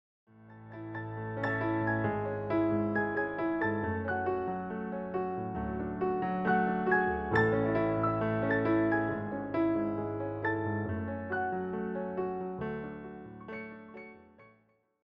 solo piano
Just calm and relaxing renditions of these well-known songs.